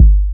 Tm8_Kick3.wav